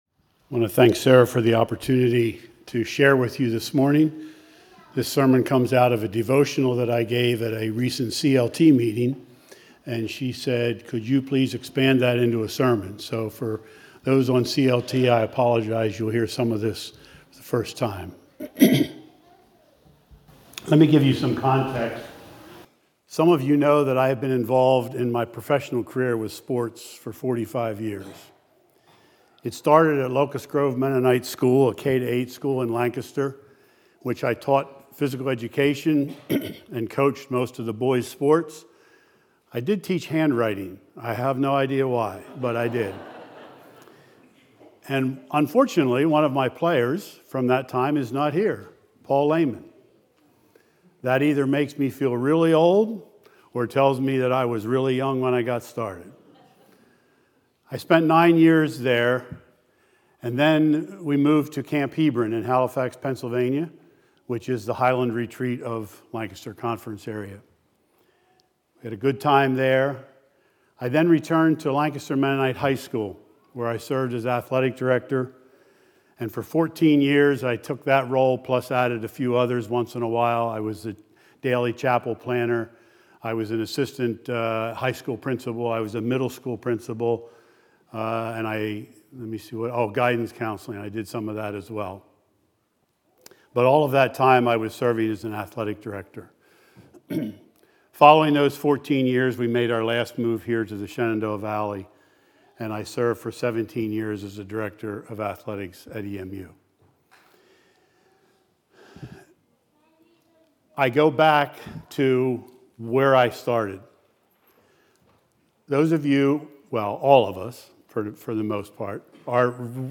2 Peter 1:3-9 Order of worship/bulletin Youtube video recording Sermon audio recording.